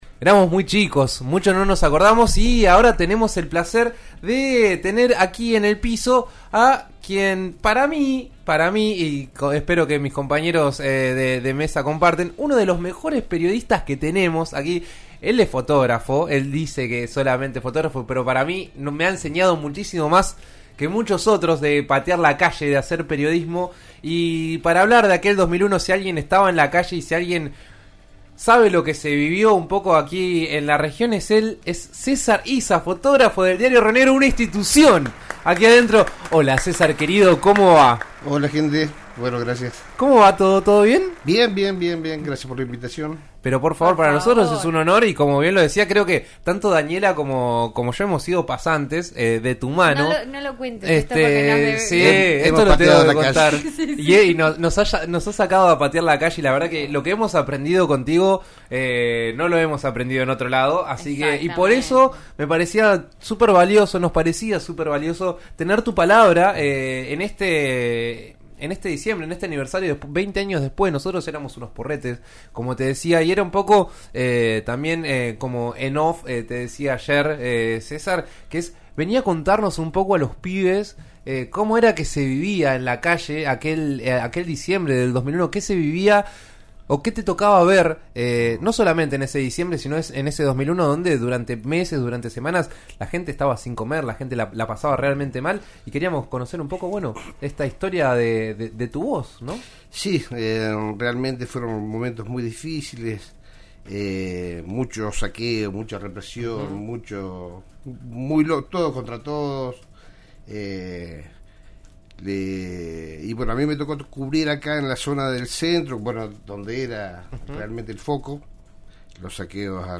En eso estamos de RN Radio (89.3) tuvo como invistado en el estudio